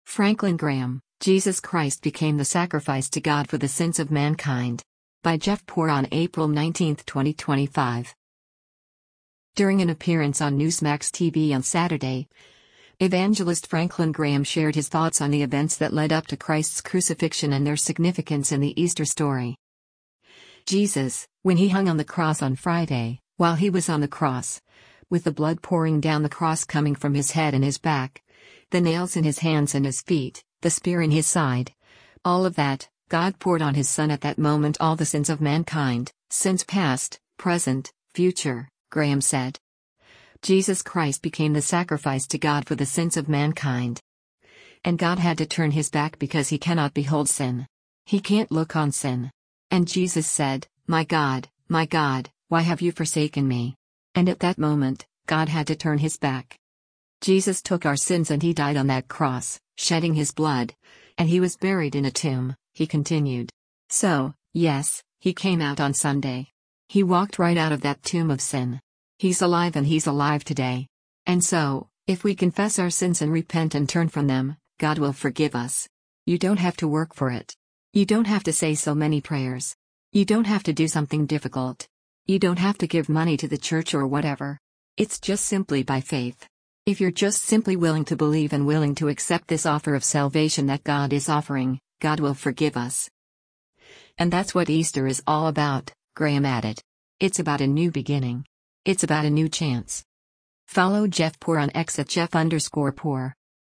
During an appearance on Newsmax TV on Saturday, evangelist Franklin Graham shared his thoughts on the events that led up to Christ’s crucifixion and their significance in the Easter story.